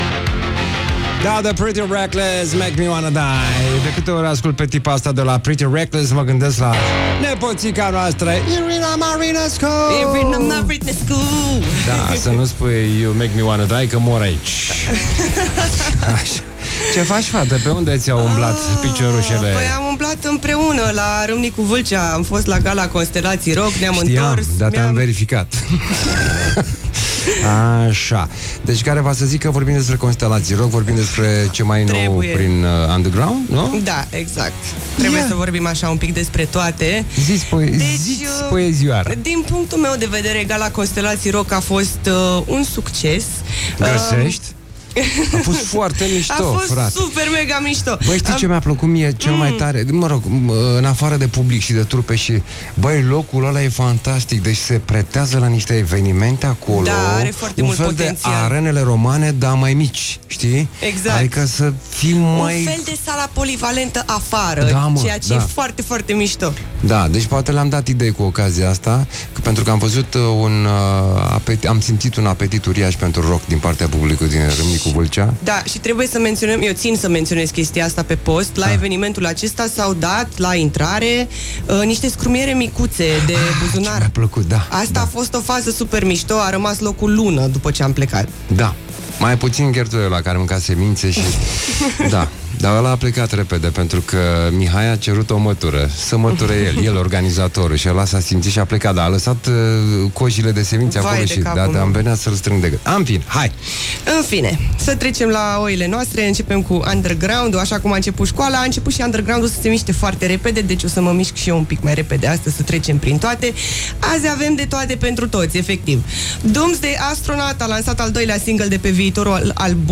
Săptămâna asta avem parte de câte ceva pe gustul fiecăruia, de la rock alternativ sensibil, la speed metal punk.